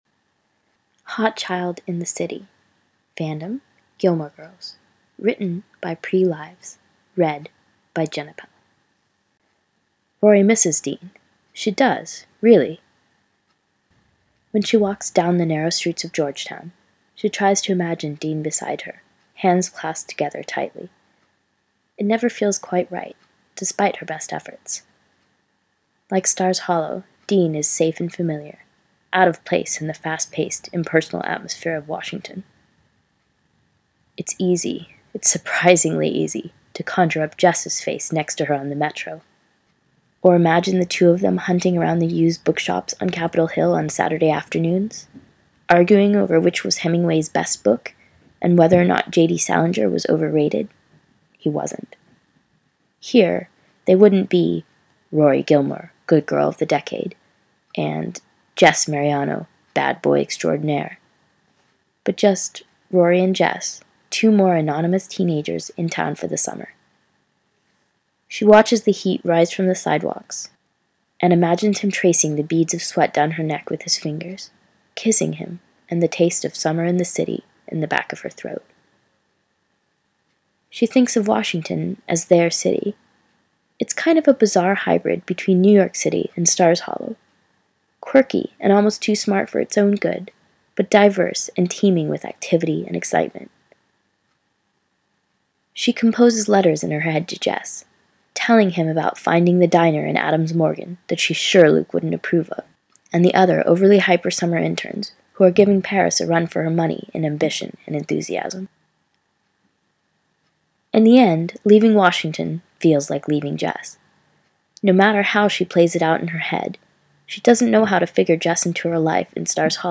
Podfic: 7 shorts for the "Awesome Ladies Ficathon"